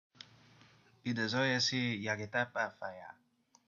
The Edazoran Unified Party (Edazoran: ʅɒɔɿɷɩɔɛȷ ɩ̆ɜʅɾɔɝɔ ɝʌɔɩɔ̊; Edazoyasi Yogetapa Phayaa; Edazoran pronunciation: